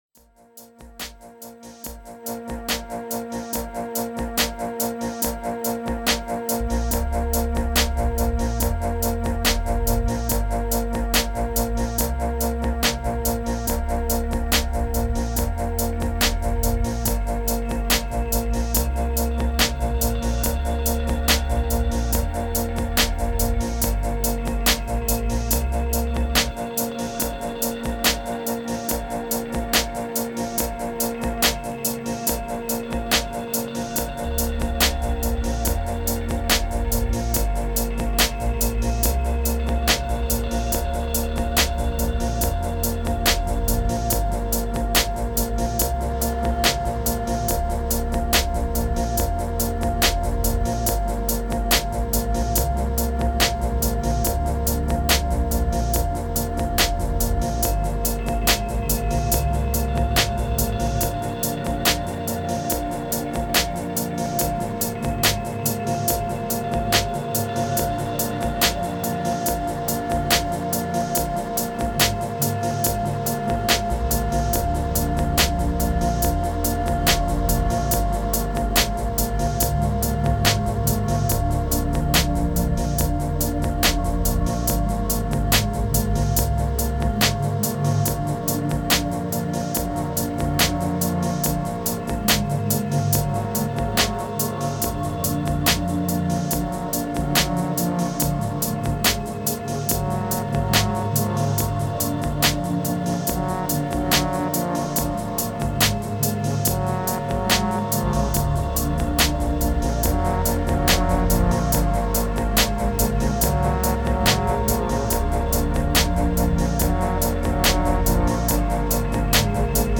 2564📈 - 96%🤔 - 71BPM🔊 - 2023-02-20📅 - 1202🌟